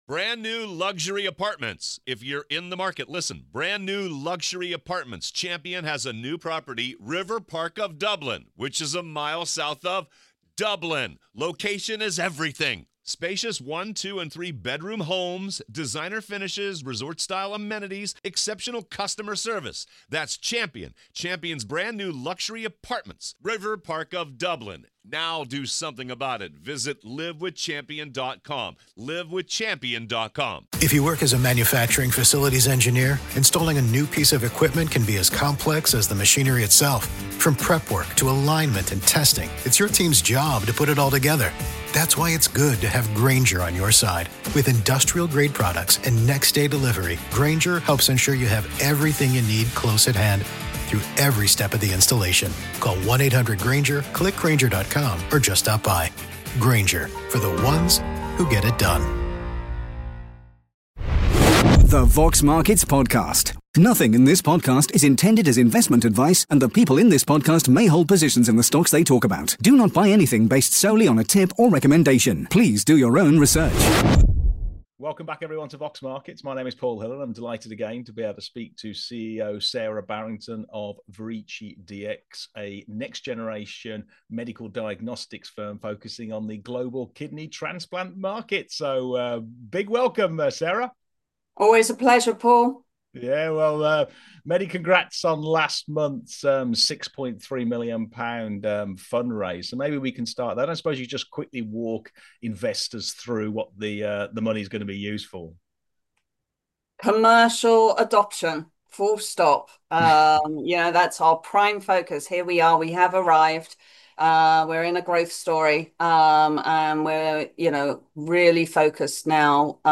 In this upbeat interview